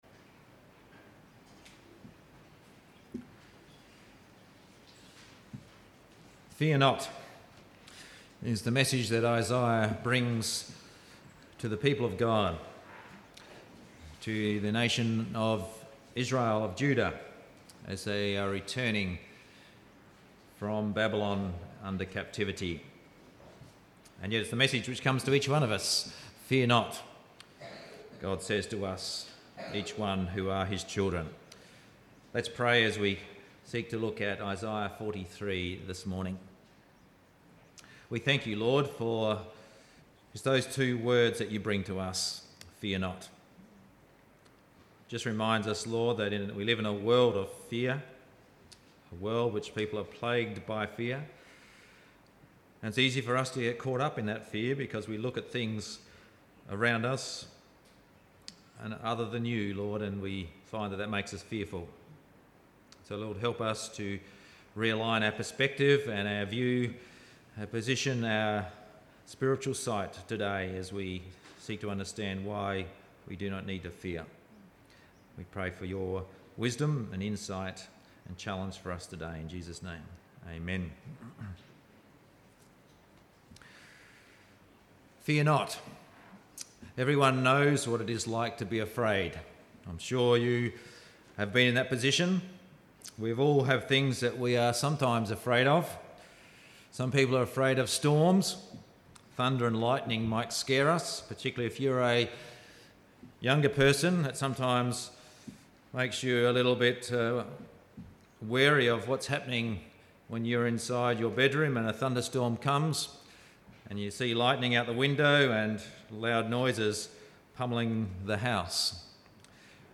5.11.17-Sunday-Service-Fear-Not-Isaiah.mp3